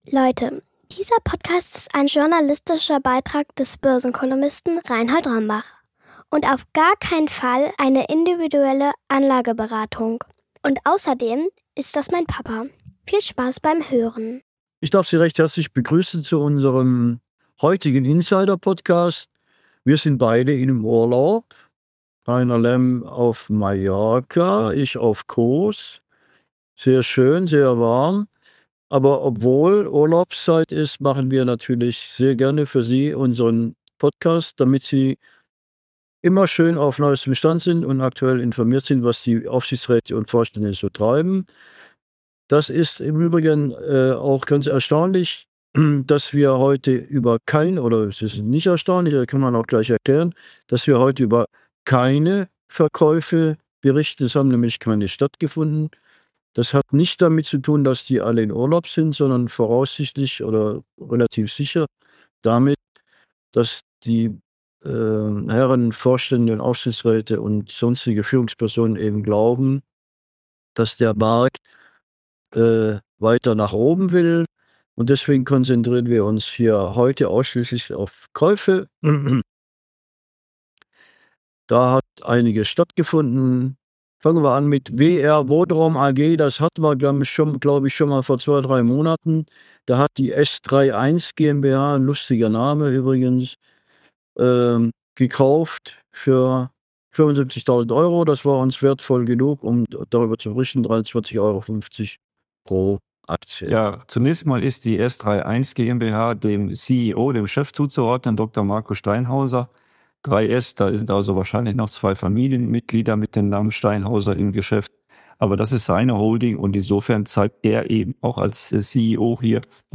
melden sich direkt von Mallorca und Kos – mit frischen Insiderinfos aus dem Maschinenraum der Börse.